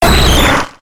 Grito de Omastar.ogg
Grito_de_Omastar.ogg